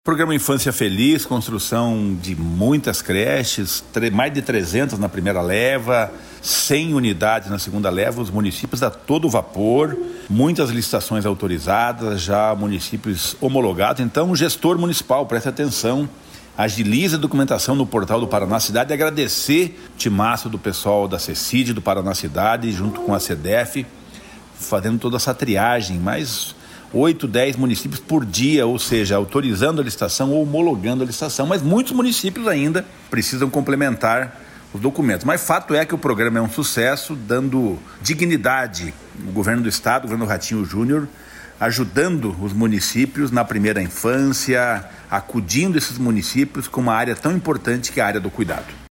Sonora do secretário do Desenvolvimento Social e Família, Rogério Carboni, sobre o Programa Infância Feliz